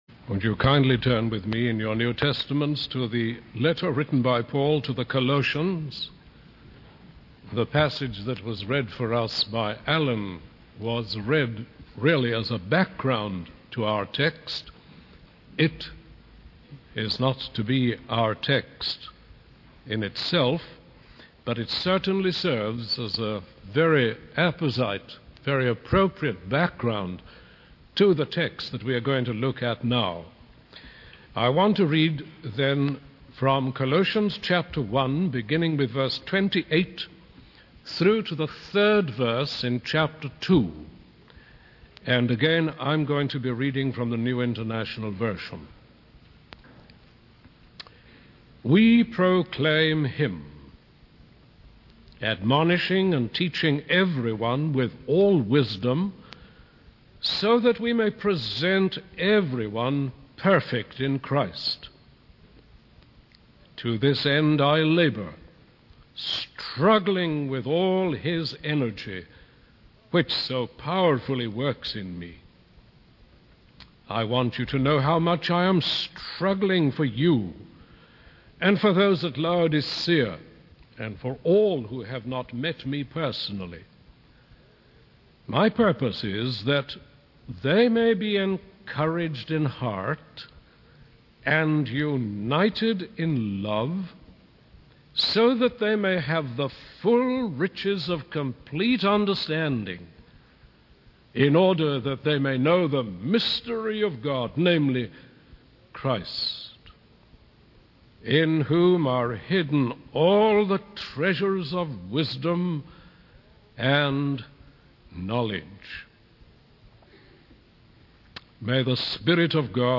In this sermon, the speaker focuses on the goal of presenting all men perfect in Christ. He emphasizes the importance of encouraging and uniting believers in love, so that they may have a complete understanding of the mystery of God, which is Christ.